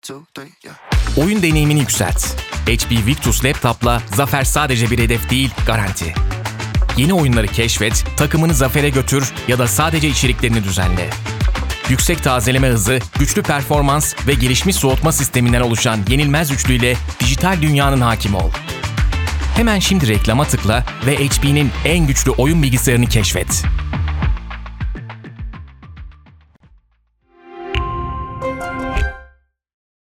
Commercieel, Jong, Natuurlijk, Warm, Zakelijk
Commercieel